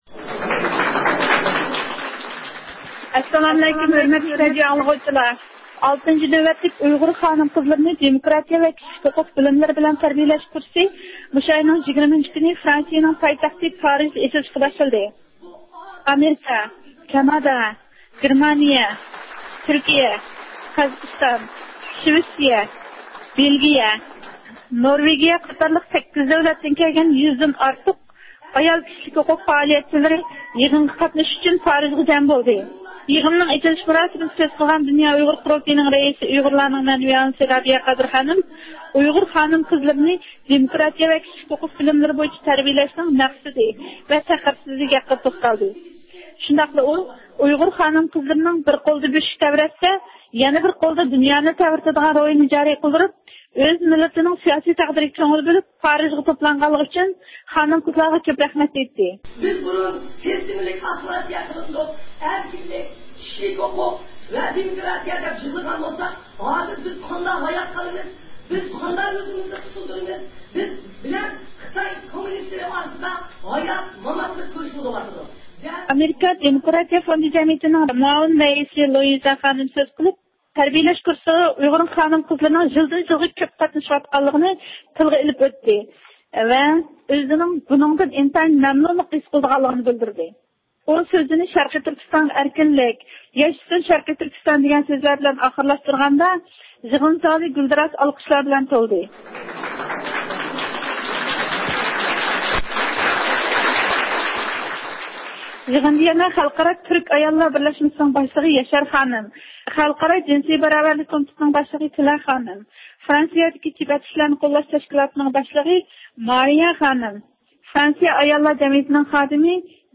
ئۇيغۇر مىللىي ھەرىكىتى رەھبىرى رابىيە قادىر خانىم يىغىننىڭ ئېچىلىش مۇراسىمىدا سۆز قىلىپ، ئۇيغۇر ئاياللىرىنىڭ مىللىي دەۋادىكى ئورنىغا يۈكسەك باھا بەردى ۋە بۇنىڭ مۇھىملىقىنى تەكىتلىدى.